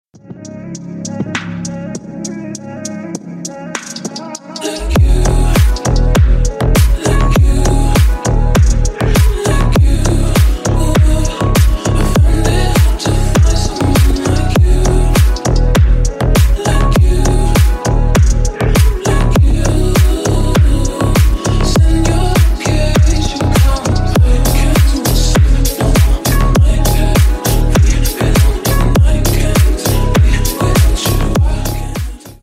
Громкие Рингтоны С Басами
Рингтоны Ремиксы
Танцевальные Рингтоны